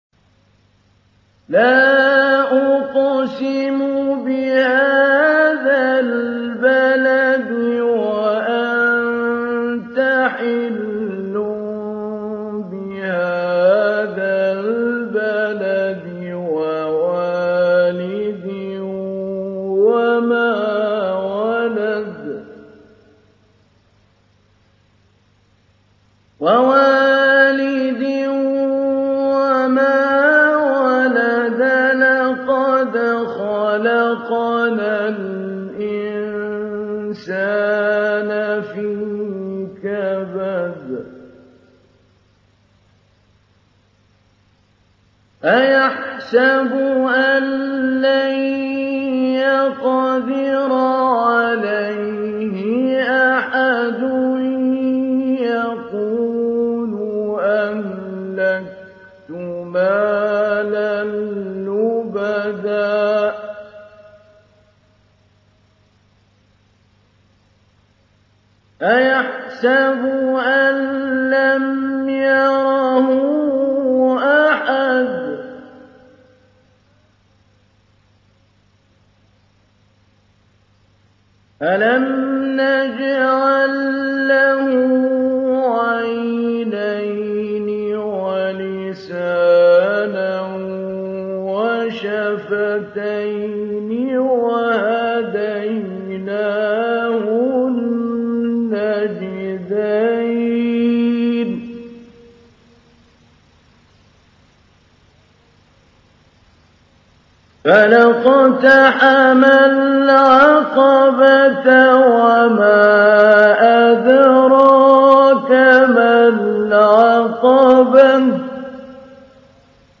تحميل : 90. سورة البلد / القارئ محمود علي البنا / القرآن الكريم / موقع يا حسين